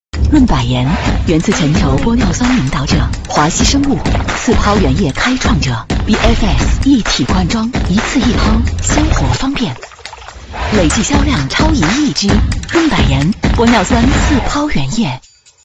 女29-广告-《润百颜》 玻尿酸
女29经铃抒情 v29
女29-广告--润百颜--玻尿酸.mp3